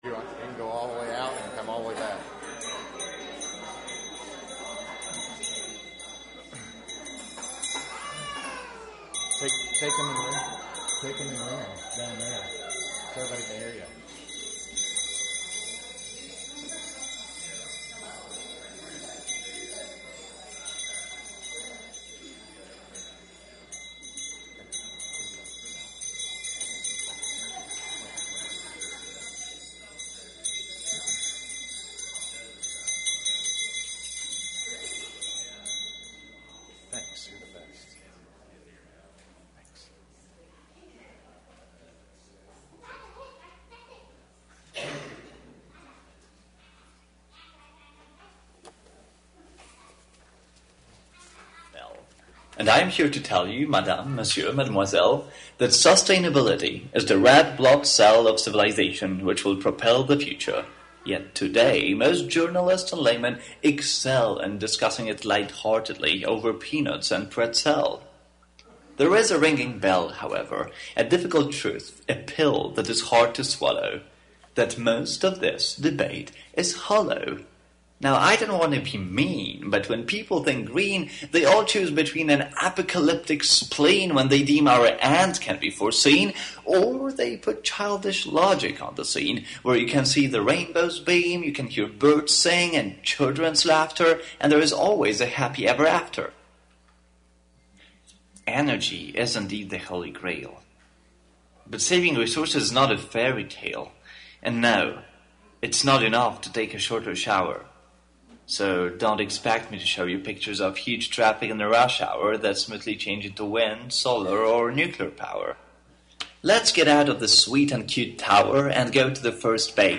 Although many of us are deeply concerned about global warming and the footprint we are leaving behind, we often struggle to quantify our consumption and to know the best way to reduce it. The UUFM Facilities Committee discusses the environmental impact of UUFM and guides us toward actions we can take to reduce our ecological footprint as a community and in our personal lives.